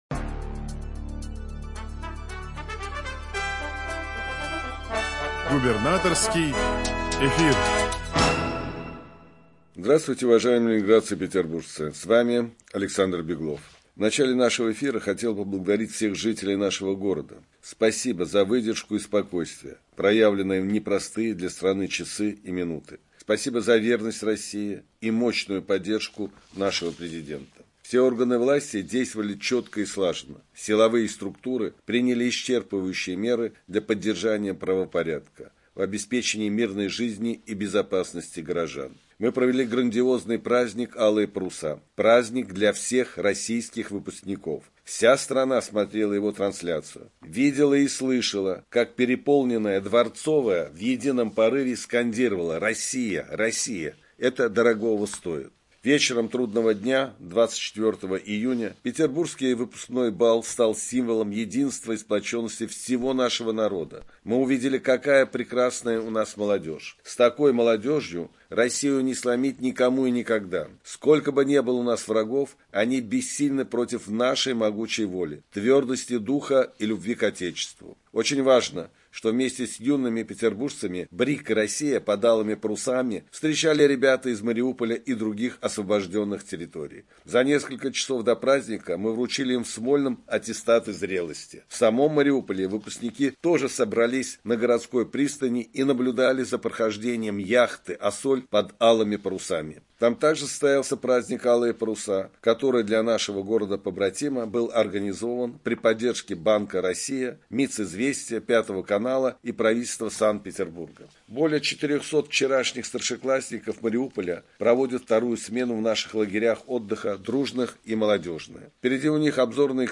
Радиообращение – 26 июня 2023 года